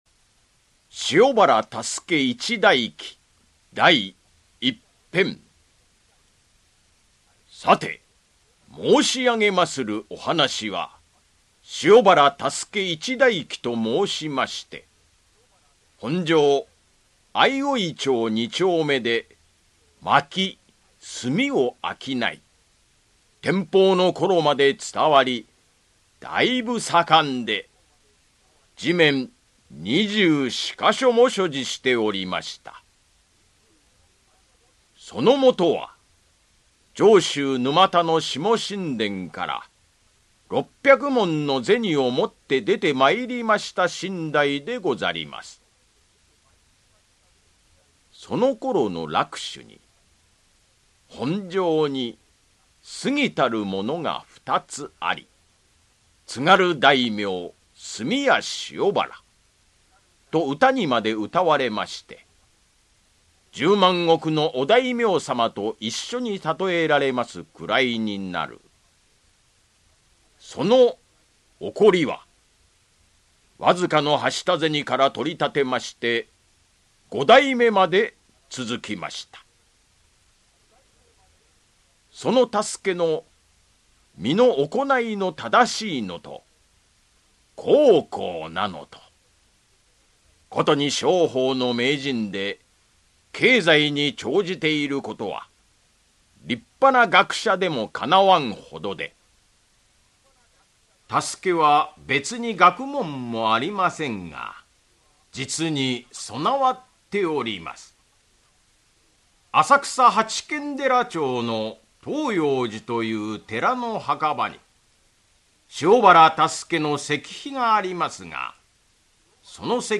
[オーディオブック] 塩原多助一代記-第一編-